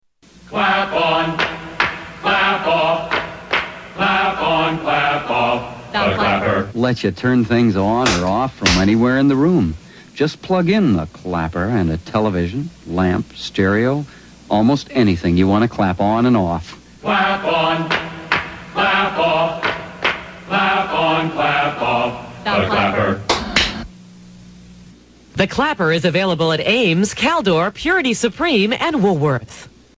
Tags: The Clapper The Clapper clips The Clapper sounds The Clapper ad The Clapper commercial